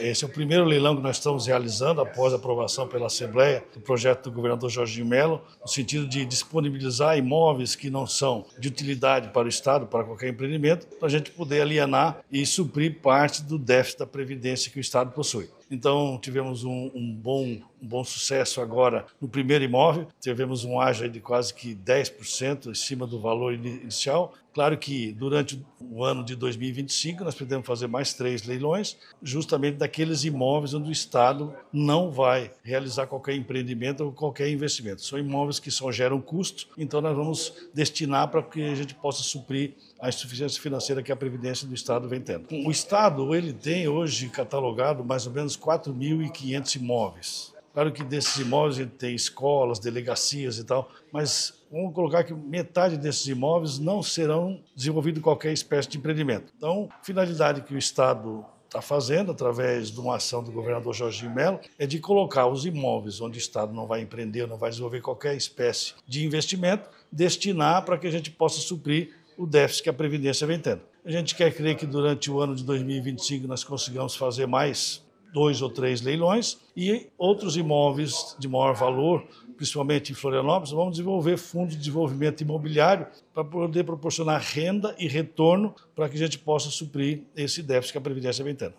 SECOM-Sonora-Secretario-Administracao-Leilao-Imoveis.mp3